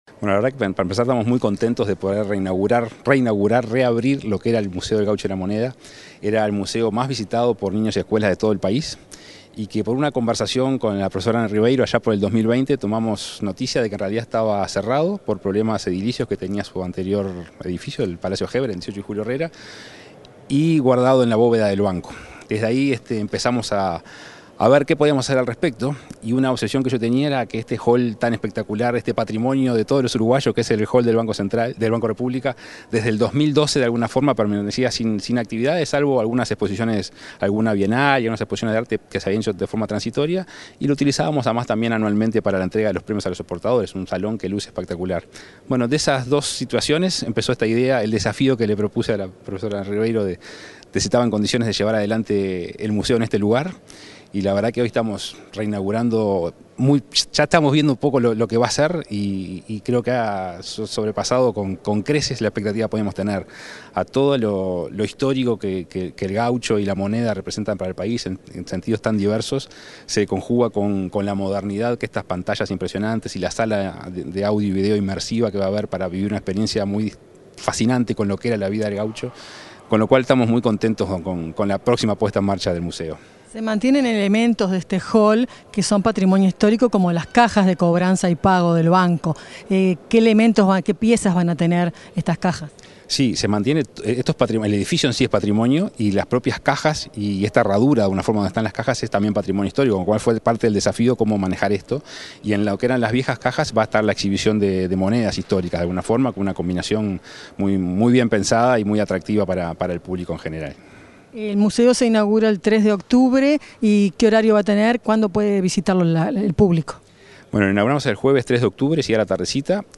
Entrevista al presidente del BROU, Salvador Ferrer